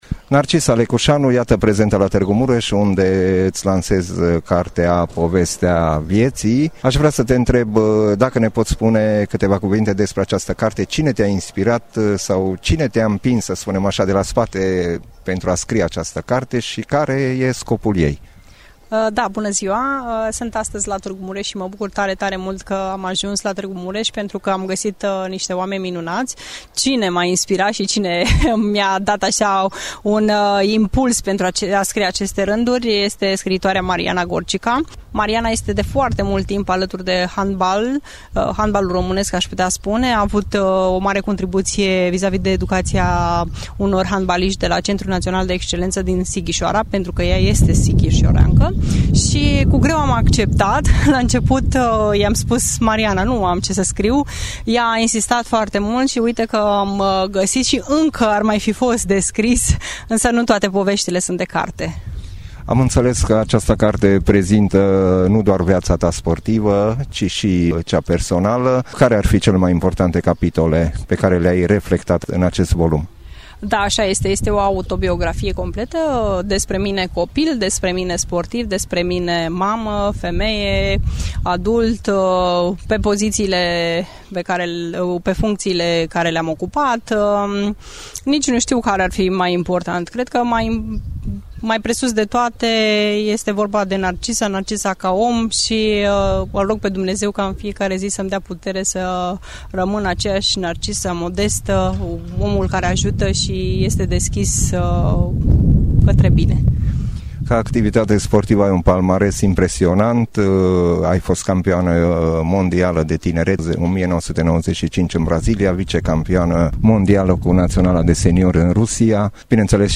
Fosta mare handbalistă Narcisa Lecuşanu și-a lansat, marți, la Târgu Mureș, volumul autobiografic intitulat „Povestea vieţii mele”. La evenimentul, găzduit de sala de sport a Gimnaziului “Liviu Rebreanu”, au fost prezenți profesori, antrenori de handbal, prieteni ai fostei jucătoare, foarte muți copii care practică handbal în școlile mureșene, precum și părinți ai acestora.